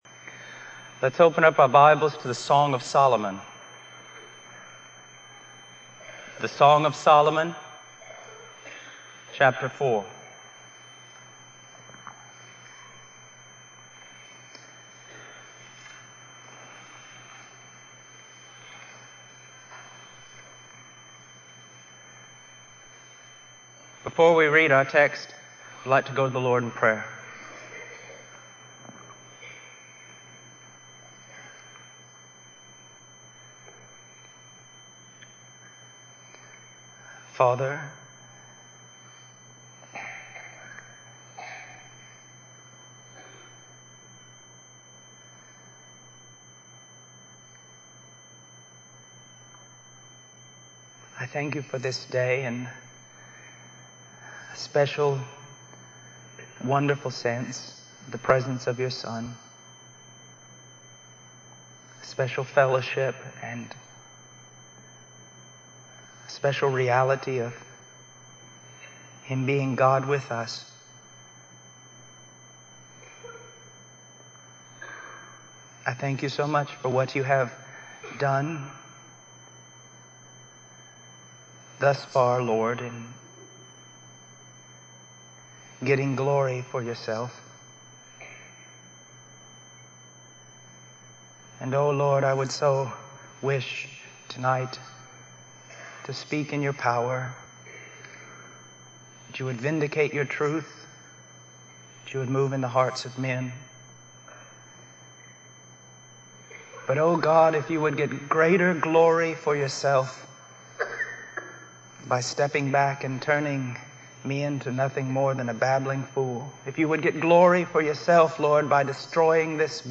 In this sermon, the preacher discusses the purpose of marriage as a revelation of God's goodness to His church. He emphasizes the importance of theology and doctrine in understanding God's passion for His people.